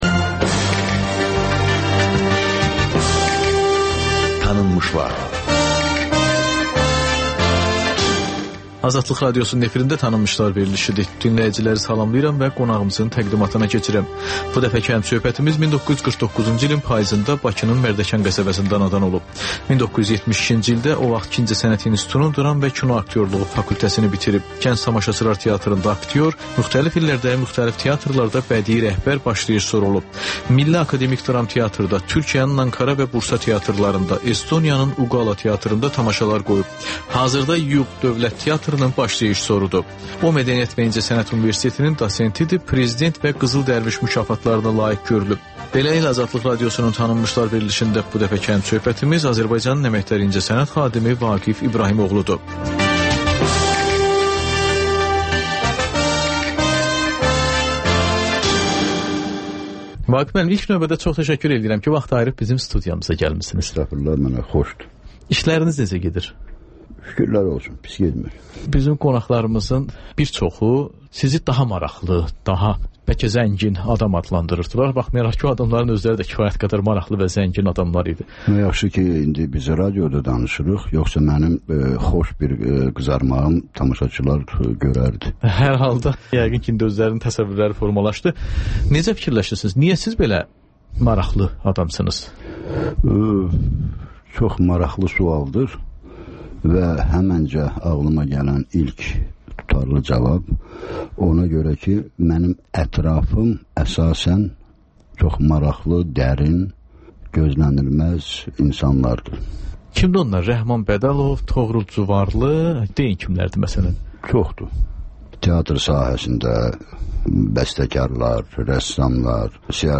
Ölkənin tanınmış simalarıyla söhbət (Təkrar)